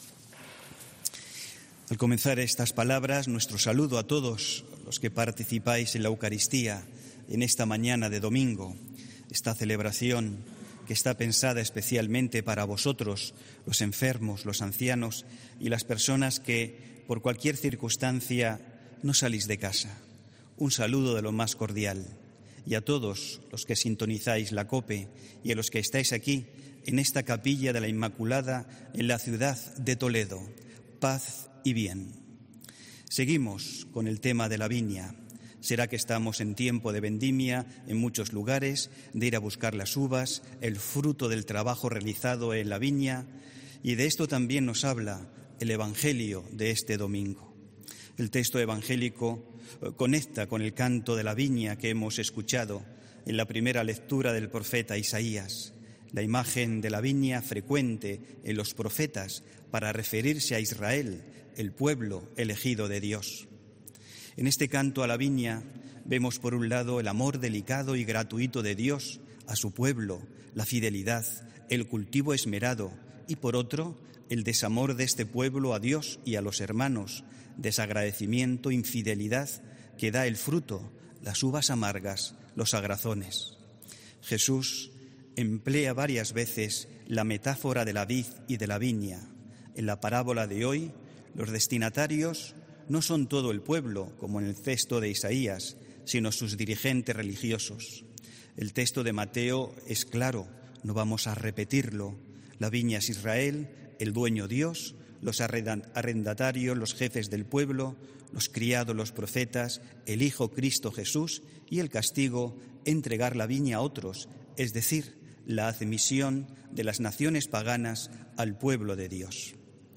HOMILÍA 4 OCTUBRE 2020